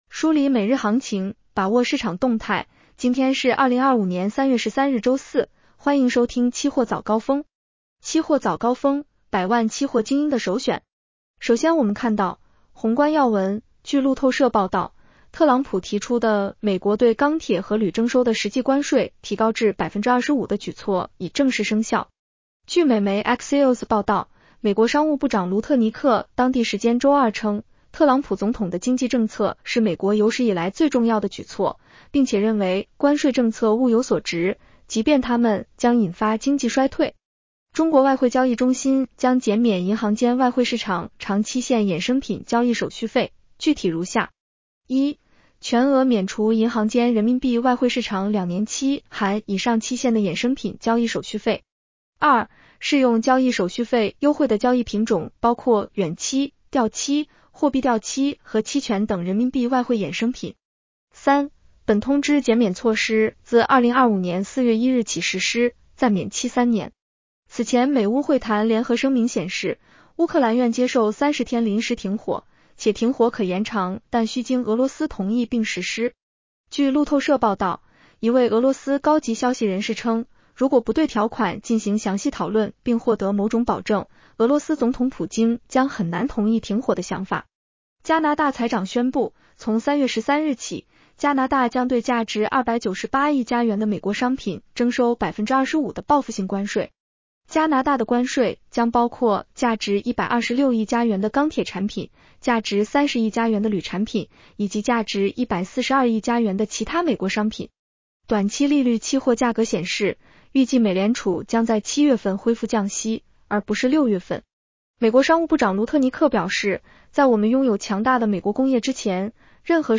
期货早高峰-音频版 女声普通话版 下载mp3 宏观要闻 1.据路透社报道， 特朗普提出的美国对钢铁和铝征收的实际关税提高至25%的举措已正式生效 。